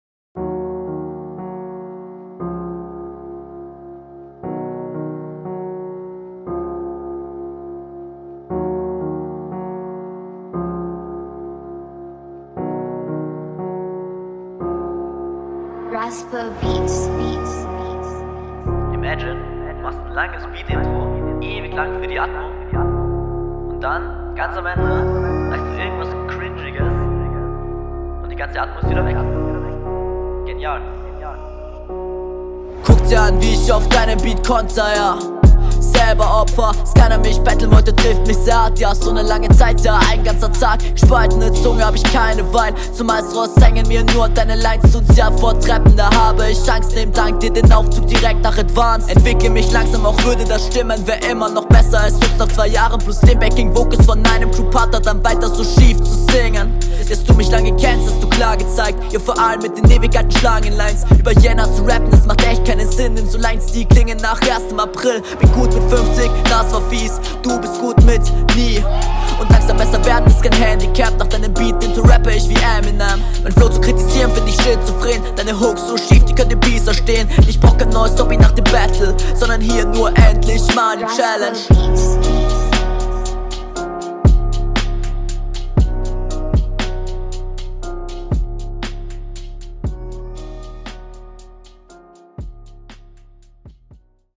Flow: Oh unerwaretet fresh, dachte eher so das der beat dir nicht liegt.